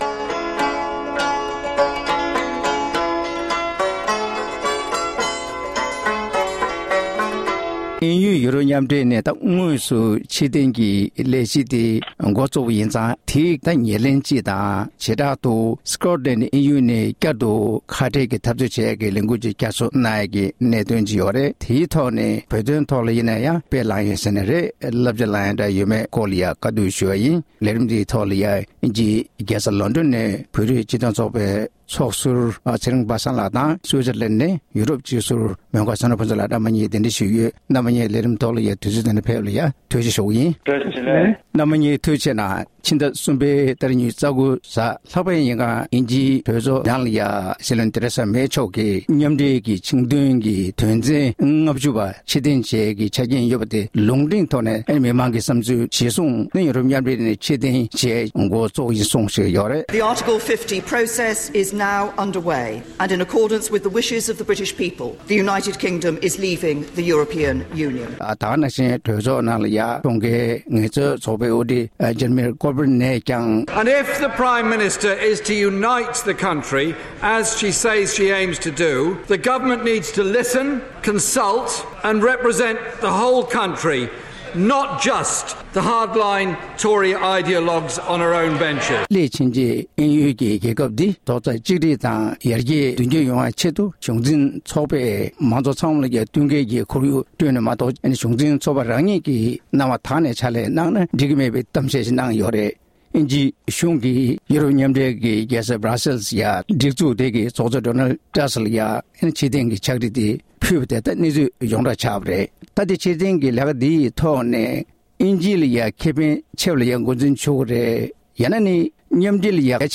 གླེང་མོལ་ཞུས་པར་གསན་རོགས་གནང་༎